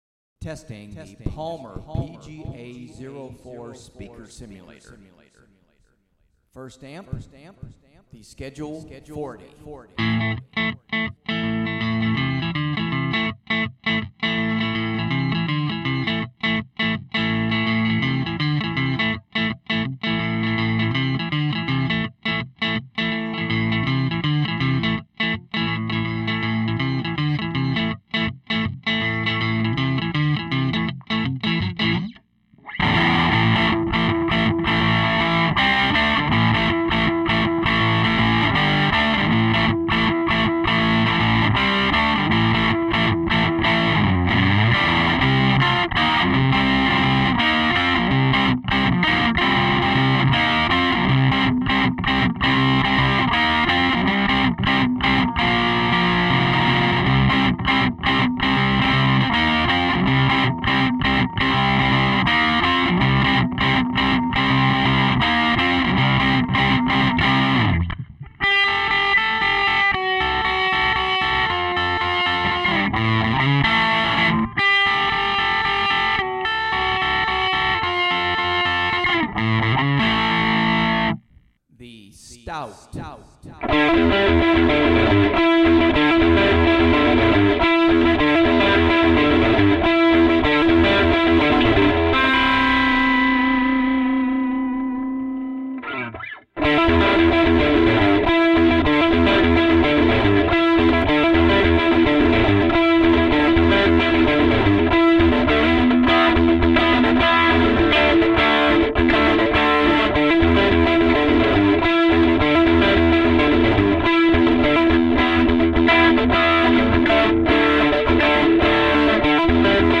I am testing it without any cabinet at all.
I did some test with a Hoffman AB763 amp and some ZZ top overdriven sounds from several amps to see how it fares.
This is a conglomerate MP3 of 4 amp clipss.
No speaker cabinet was used.
It's very crude and raw.
An original Hoffman Plexi 50.